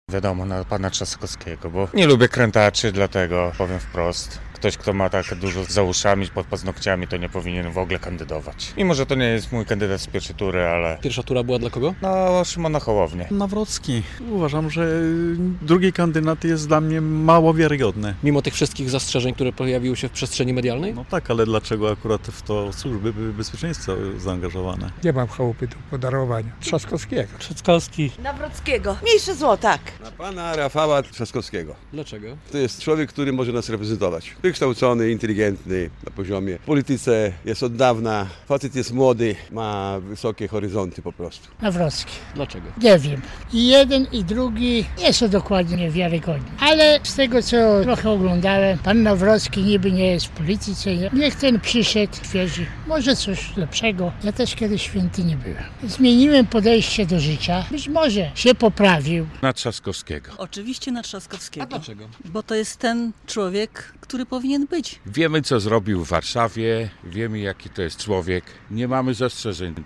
Na kogo głosowali mieszkańcy Łomży? - relacja